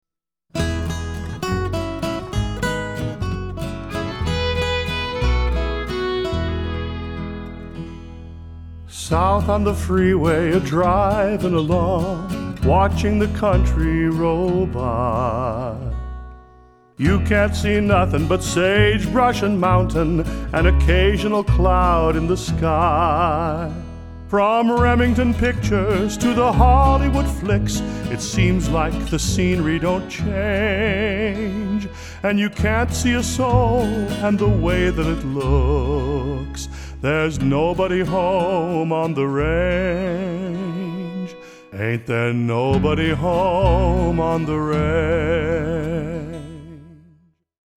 has always wanted to do a Gospel album